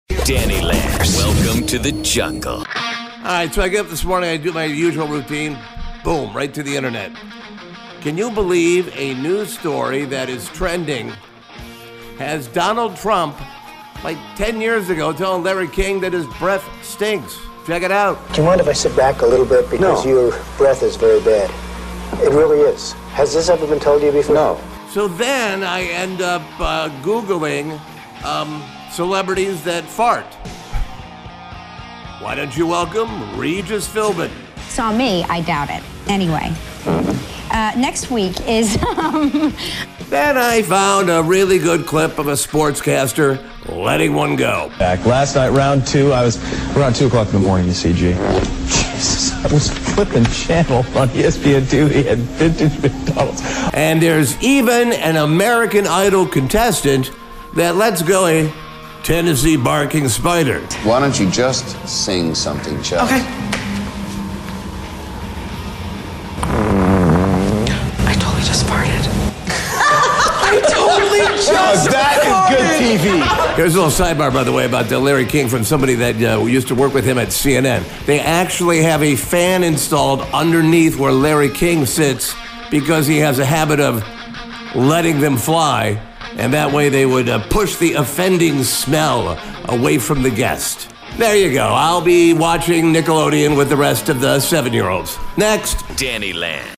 I put together an audio piece of not only Trump telling King to chew on a mint but celebrities who have passed gas on the air.